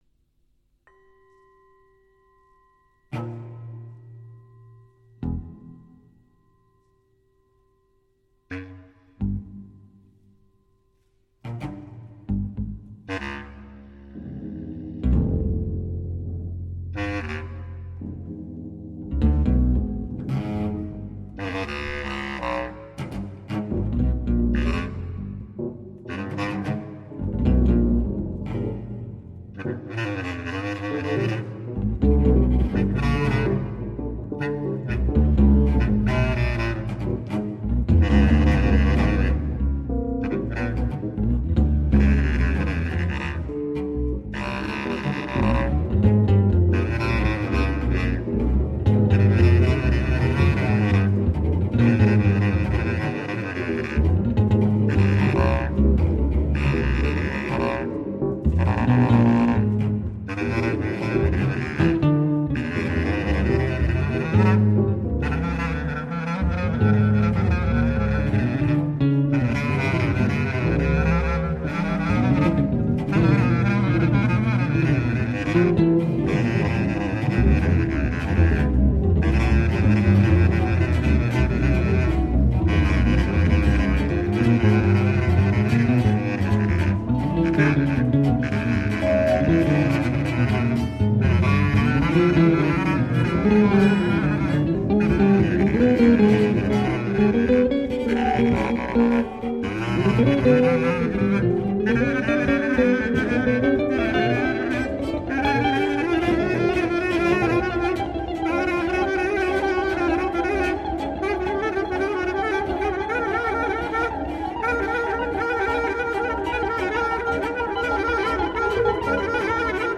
guitar & electronica
piano & electronica
bass clarinet
cello & electronica
This clip is from the very beginning of the concert.